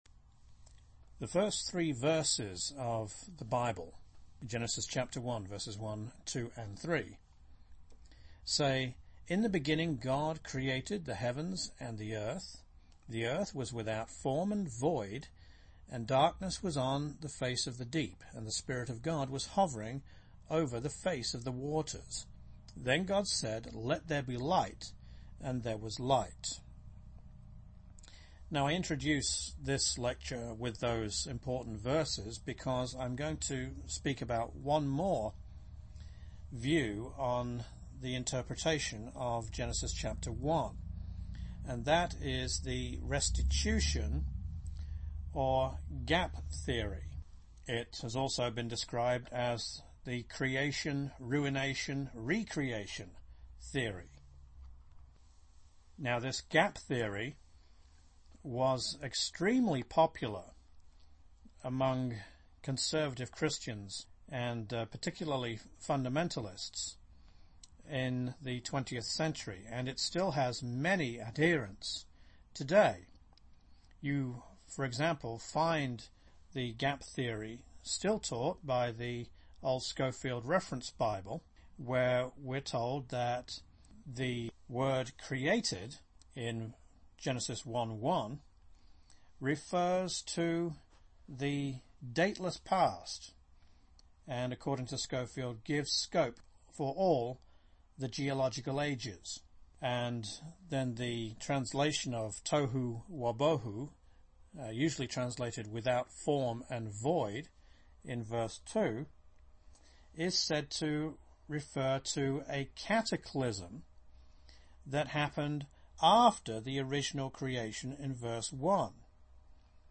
This lecture is taken from the course “The Creation of God” at Telos Biblical Institute.